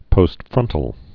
(pōst-frŭntl)